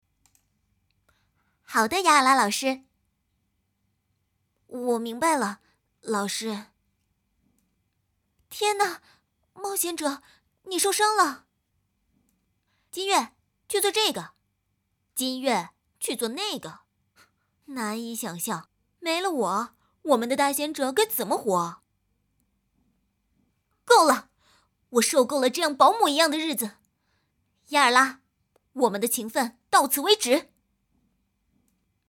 国语青年亲切甜美 、女课件PPT 、工程介绍 、绘本故事 、动漫动画游戏影视 、旅游导览 、30元/分钟女2 国语 女声 遗忘者之旅-黑月 亲切甜美